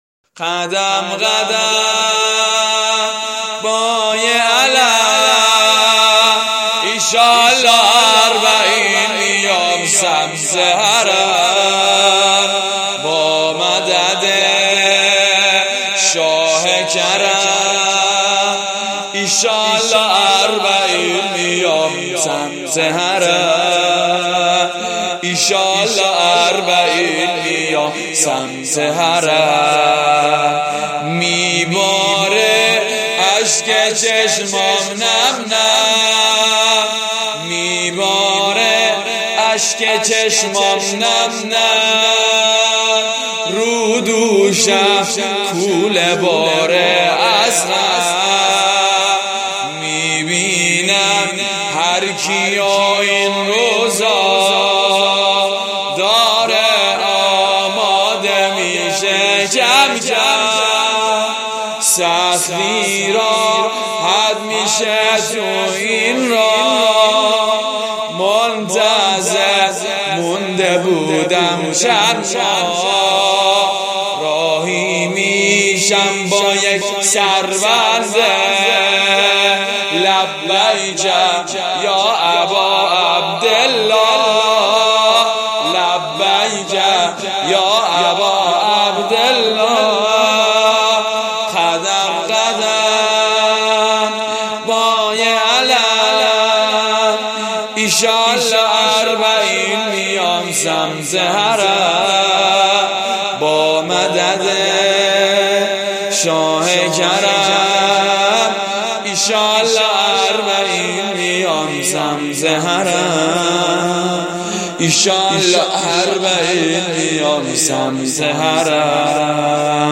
واحد بسیار زیبا /قدم قدم با یه علم